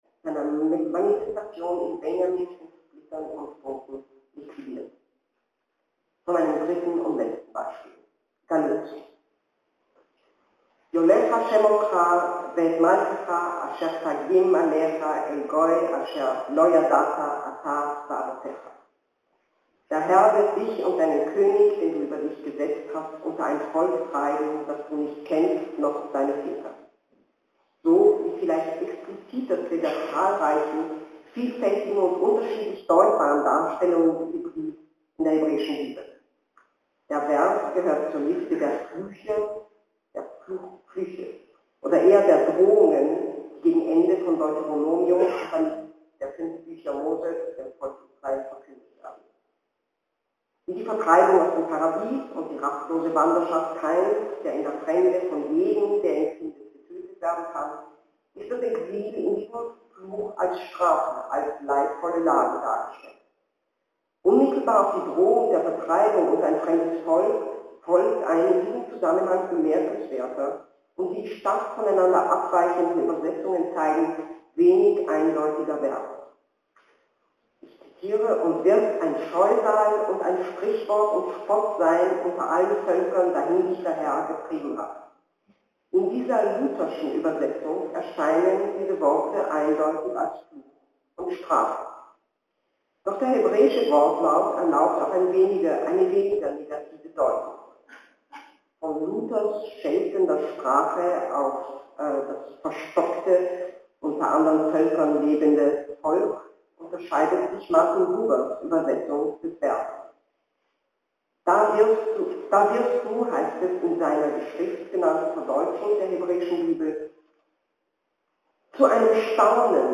Saal der Alten Handelsbörse zu Leipzig